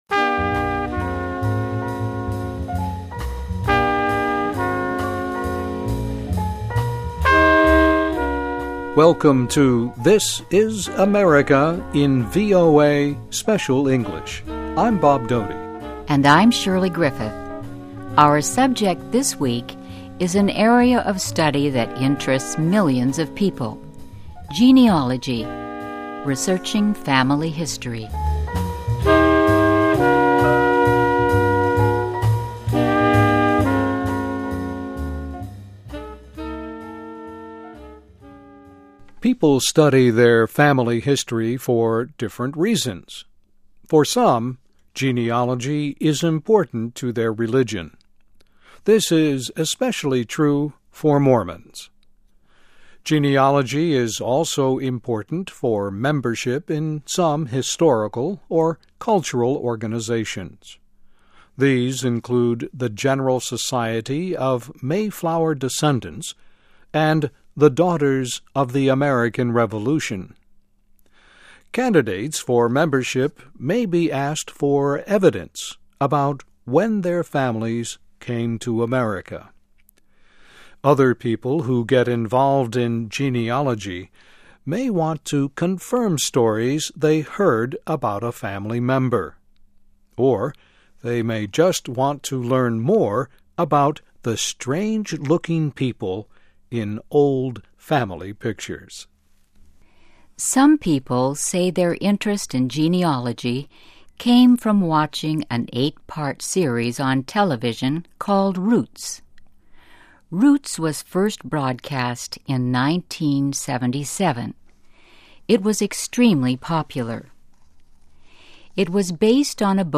USA: Great-Grandma Did What? Becoming a Family-History Explorer (VOA Special English 2006-09-17)<meta name="description" content="Text and MP3 File.
Listen and Read Along - Text with Audio - For ESL Students - For Learning English